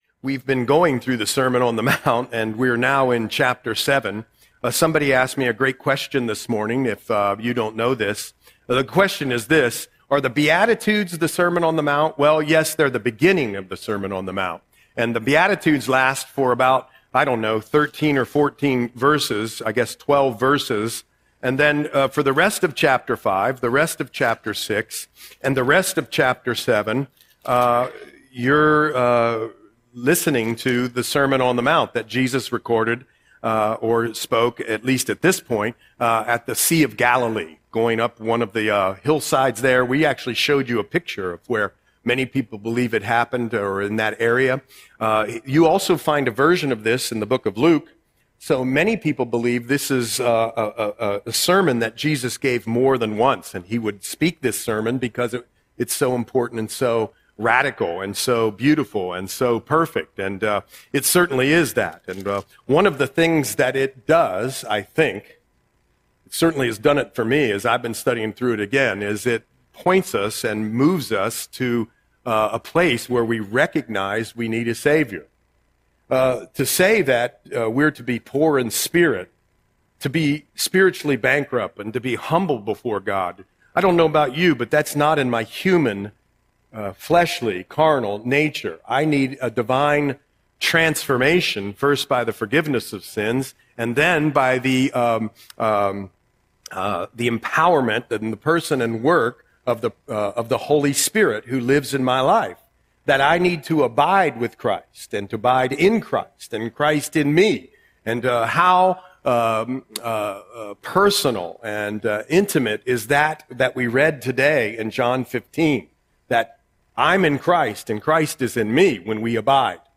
Audion Sermon - January 4, 2026